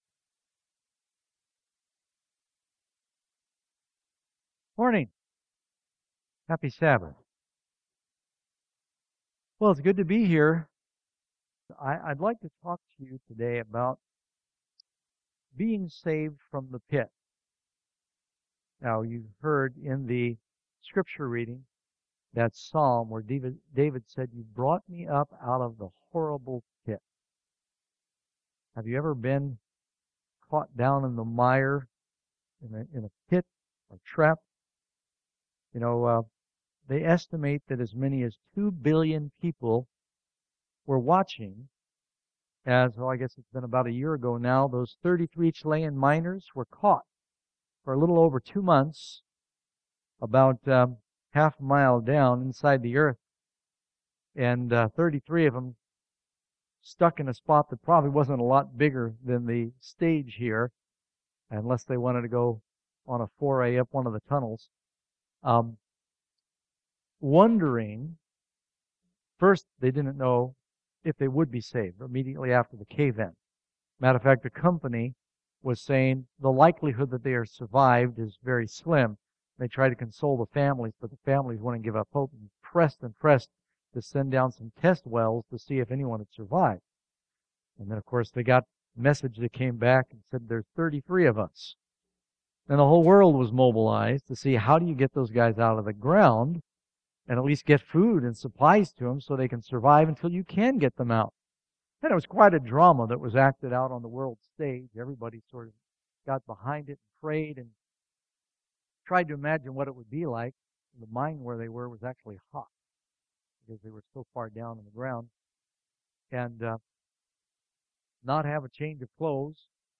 7-7-12sermon.mp3